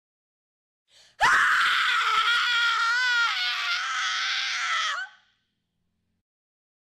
دانلود صدای جیغ زن ترسناک 4 از ساعد نیوز با لینک مستقیم و کیفیت بالا
جلوه های صوتی